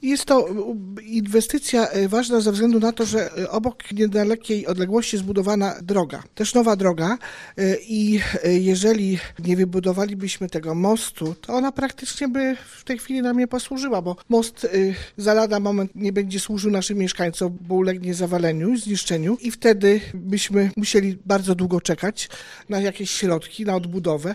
Inwestycja jest bardzo potrzebna, podkreśla Wójt Andrzejewa.